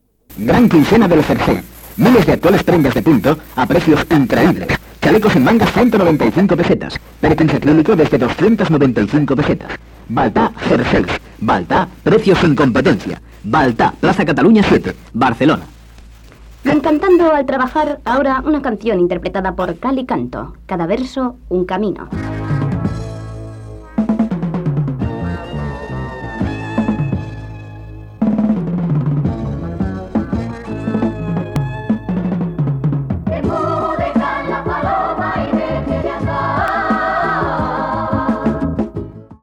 Publicitat, identificació del programa i tema musical
Musical